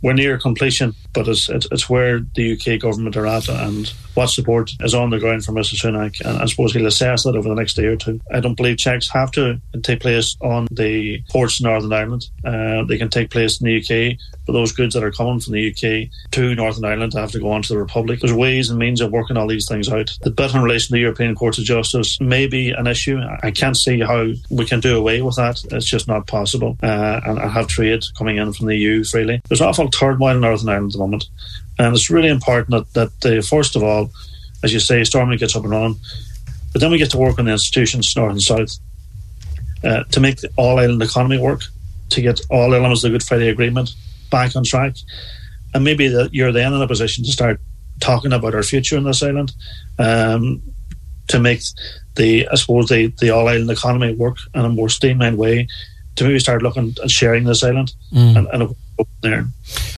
He said on this morning’s Nine ’til Noon show that people shouldn’t underestimate the pressure on Rishi Sunak from former Prime Minister Boris Johnston and that if the DUP has 6 out of their 7 demands met, he would consider that a success for them.